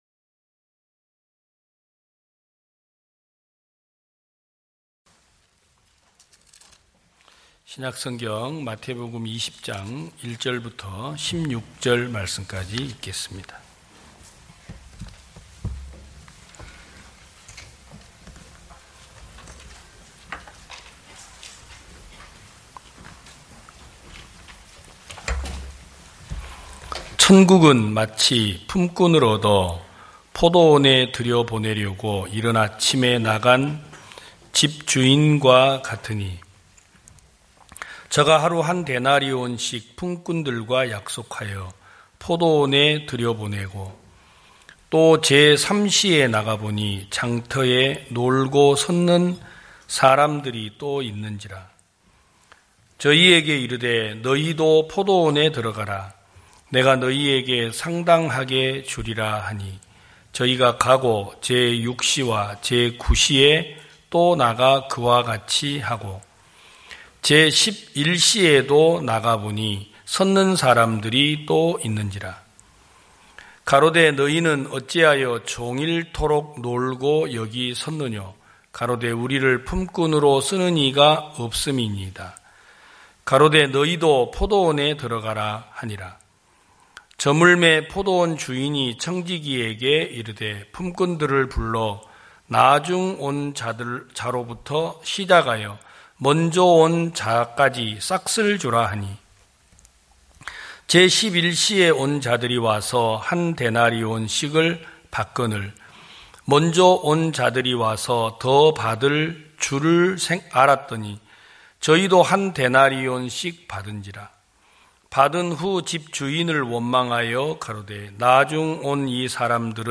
2021년 11월 21일 기쁜소식부산대연교회 주일오전예배
성도들이 모두 교회에 모여 말씀을 듣는 주일 예배의 설교는, 한 주간 우리 마음을 채웠던 생각을 내려두고 하나님의 말씀으로 가득 채우는 시간입니다.